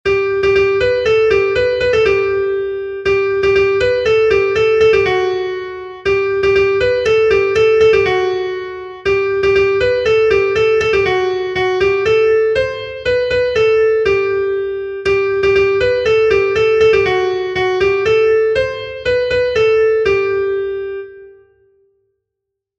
Air de bertsos - Voir fiche   Pour savoir plus sur cette section
Dantzakoa
Herri trikiti doinu hau
Seiko berdina, 3 puntuz eta 8 silabaz (hg) / Hiru puntuko berdina, 16 silabaz (ip)